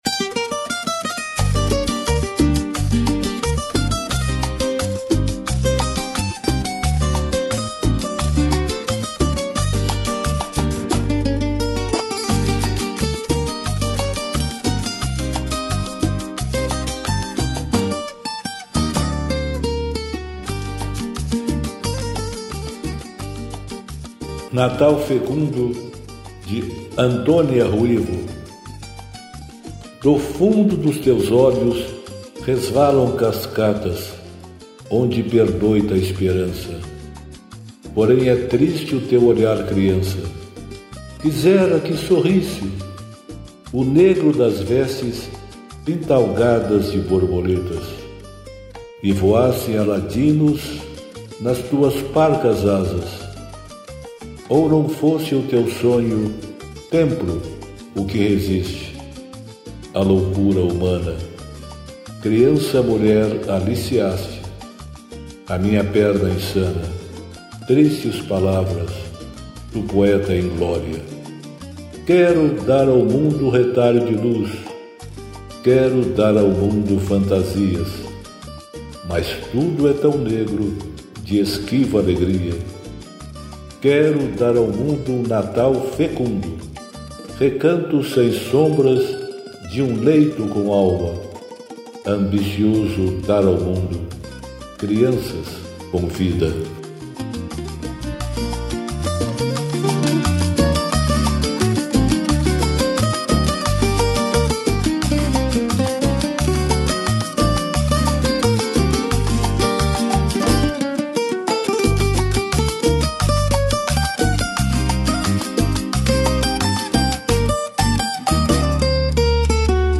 música e arranjo: IA